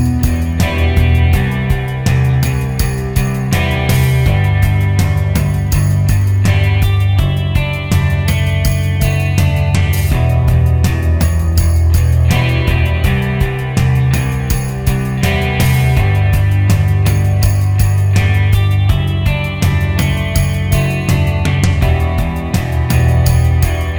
Backing tracks for female or girl singing parts.